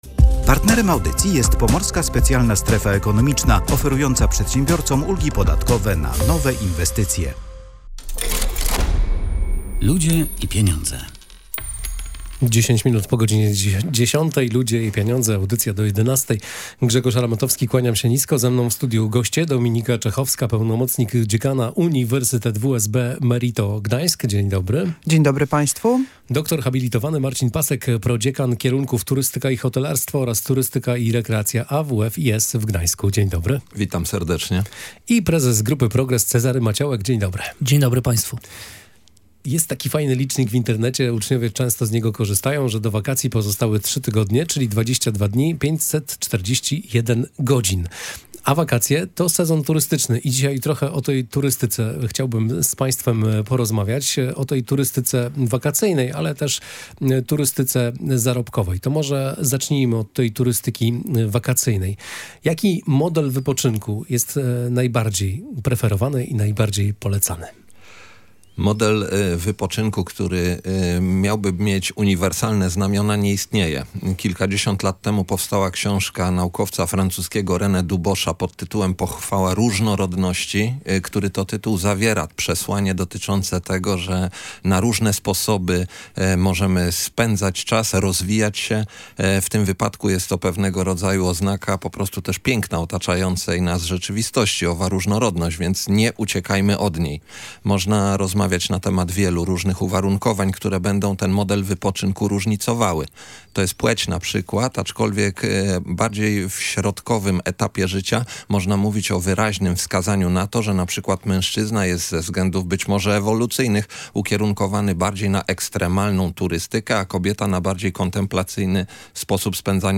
Między innymi o tym mówiliśmy w audycji „Ludzie i Pieniądze”.